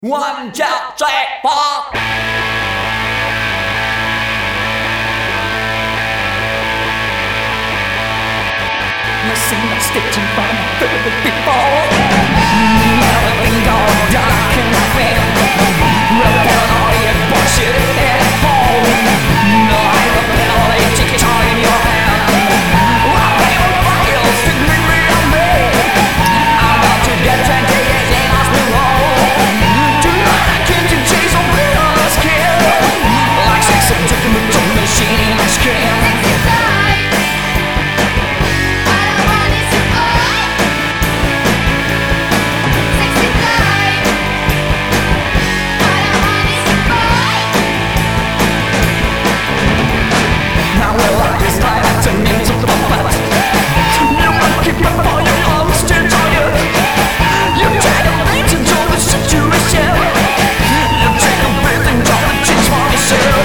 Rock Bubble Gun mutant, survolté et plein de féminité